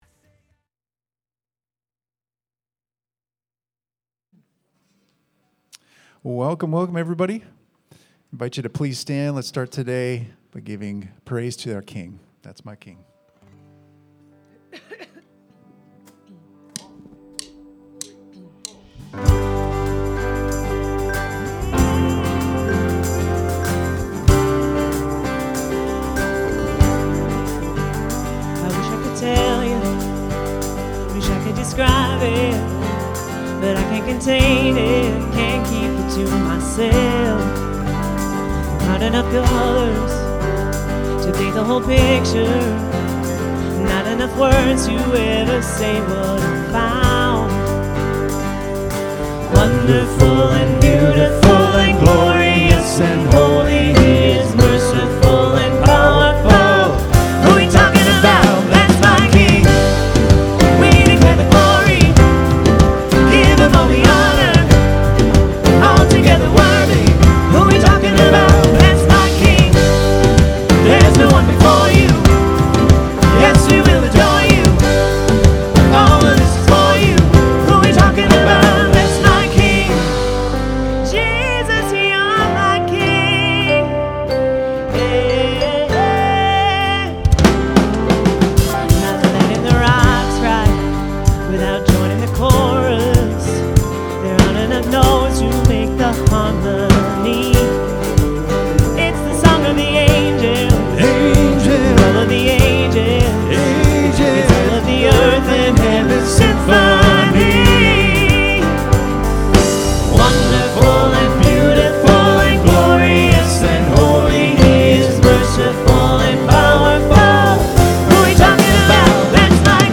Sunday Sermon: 11-23-25